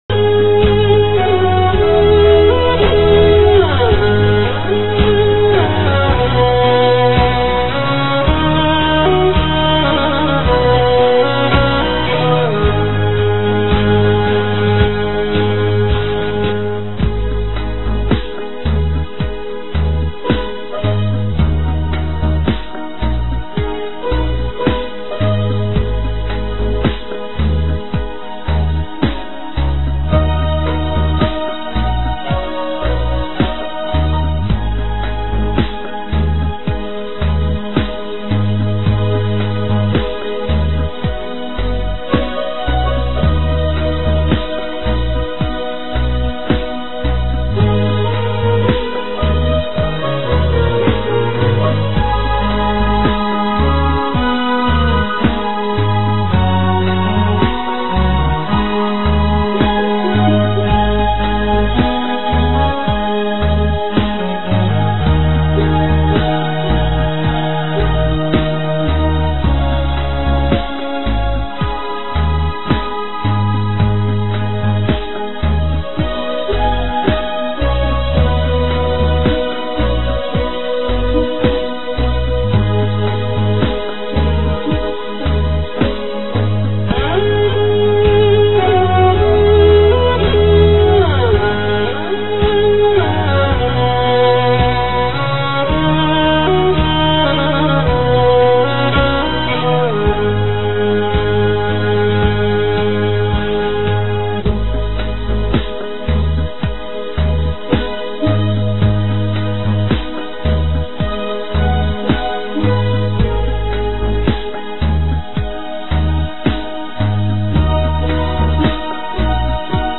伴奏：